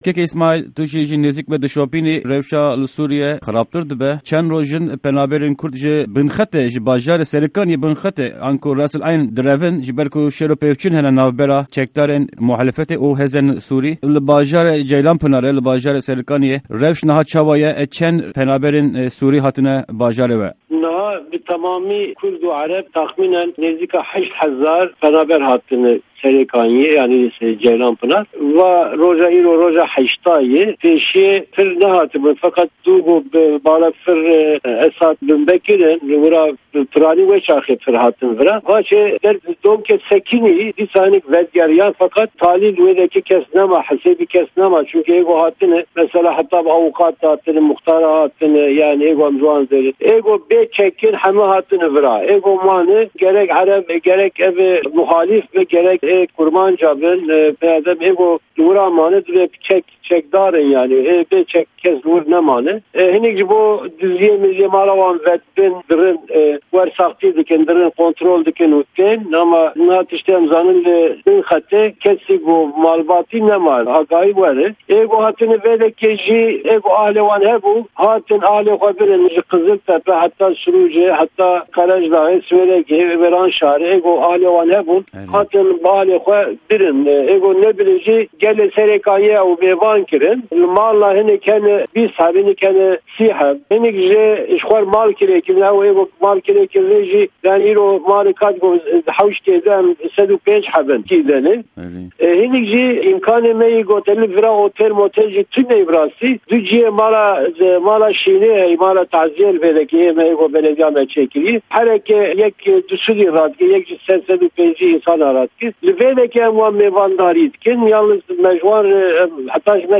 Di hevpeyvîna Pişka Kurdî ya Dengê Amerîka de bajarvanê Ceylanpinar'ê Îsmaîl Arslan ji Partîya Aştî û Demokrasîyê (BDP)li rewşa penabêrên Sûrî agahîyan dide.